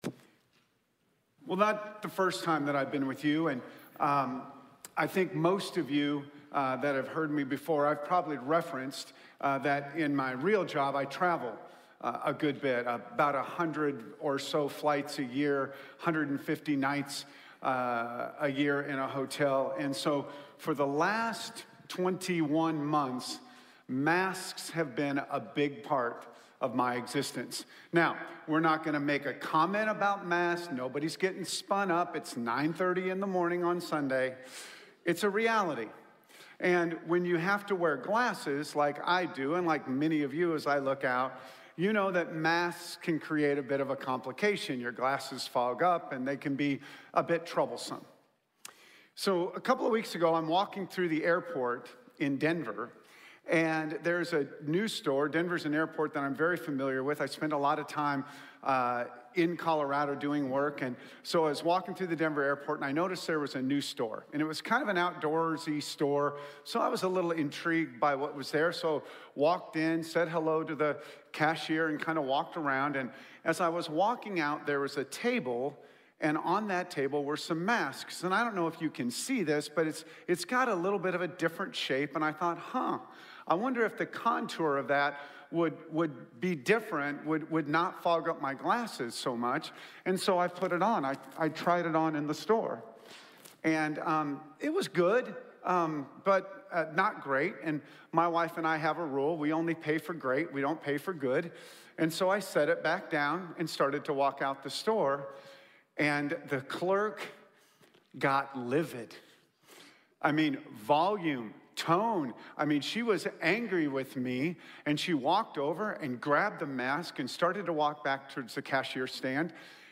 Siervos y mayordomos | Sermón | Iglesia Bíblica de la Gracia